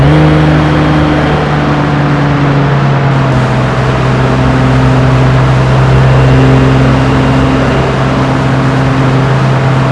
toyfj_revdown.wav